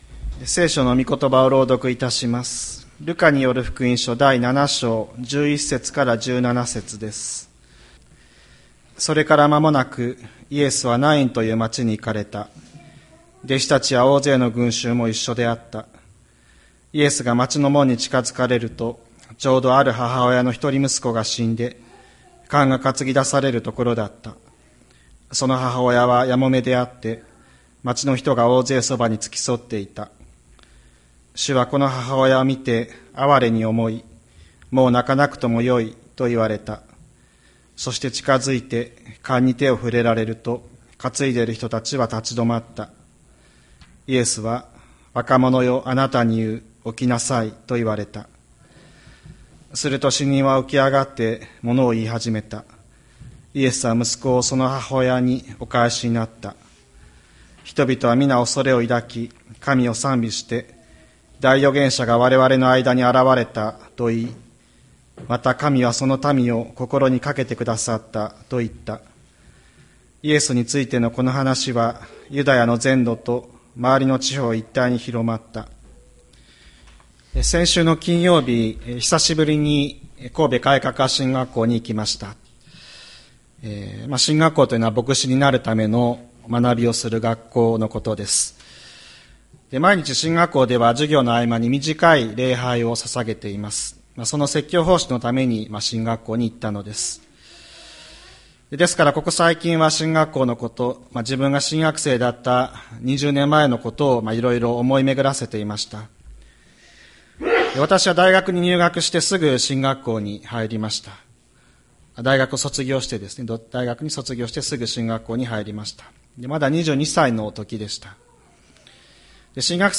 2024年10月20日朝の礼拝「近づくいのちの主」吹田市千里山のキリスト教会
千里山教会 2024年10月20日の礼拝メッセージ。